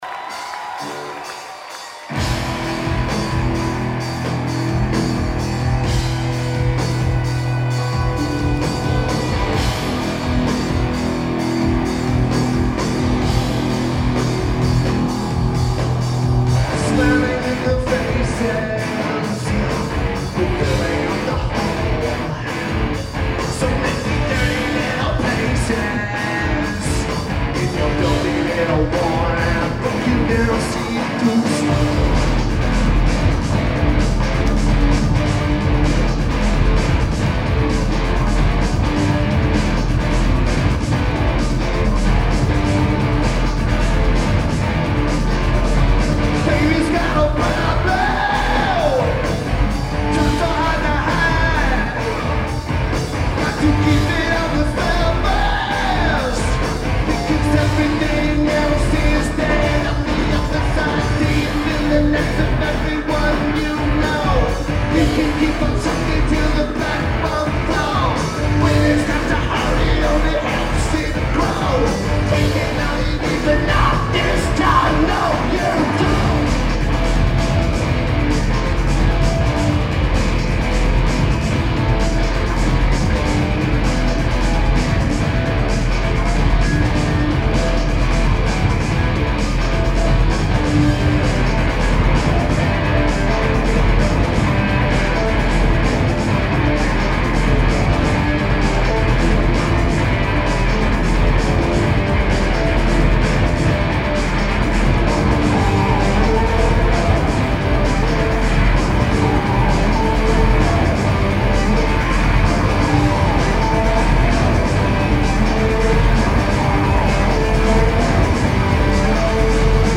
Orpheum
Once you normalize the transfer, it sounds fantastic!